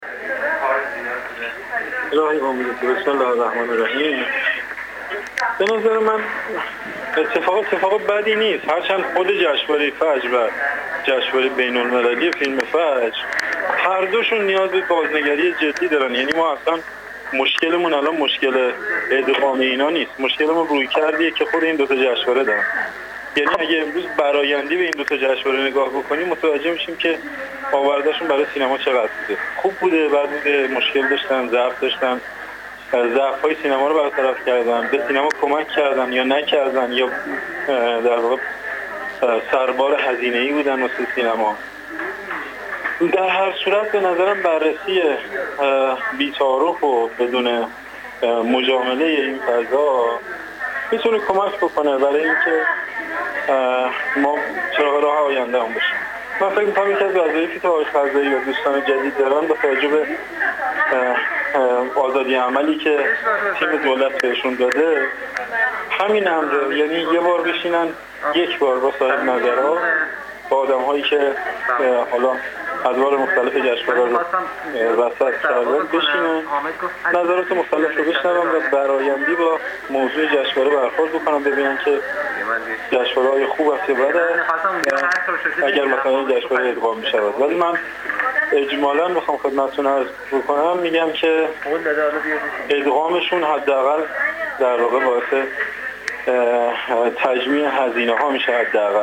منتقد و کارشناس سینما در گفت‌وگو با ایکنا